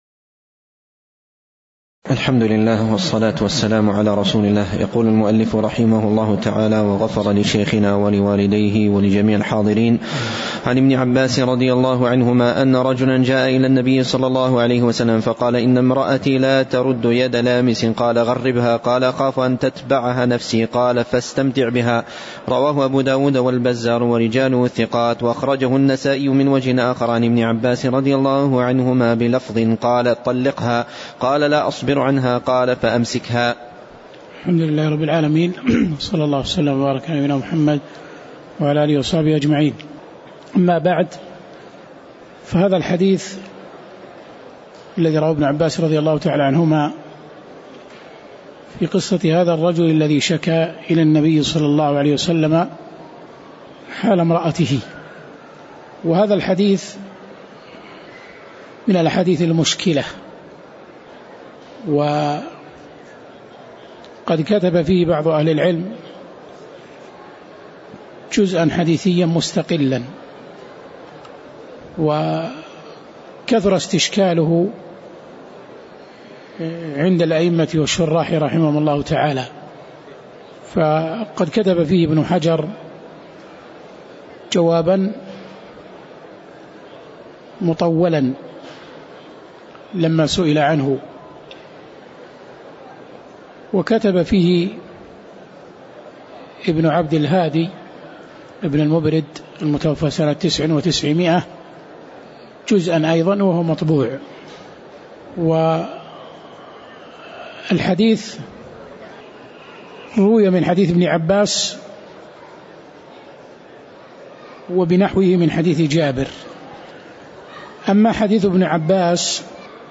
تاريخ النشر ٦ شعبان ١٤٣٨ هـ المكان: المسجد النبوي الشيخ